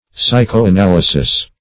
Psychoanalysis \Psy`cho*a*nal"y*sis\, n.